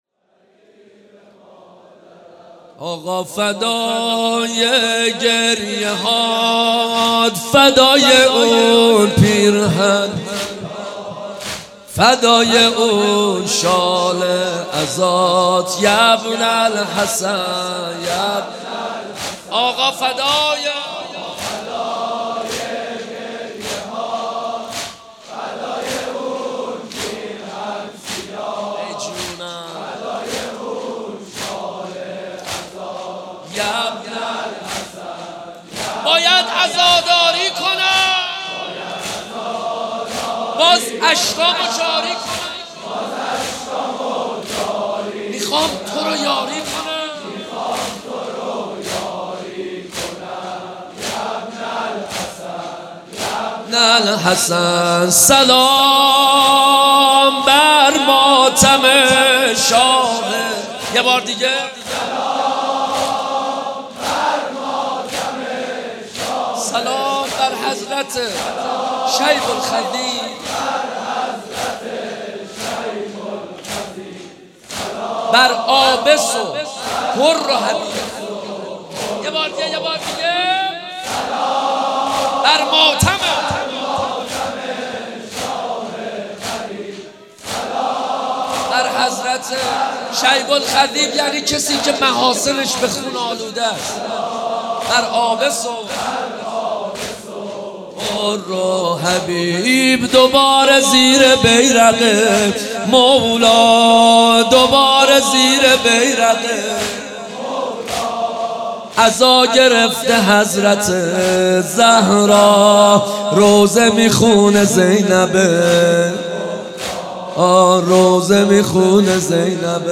مناسبت : شب اول محرم
قالب : زمینه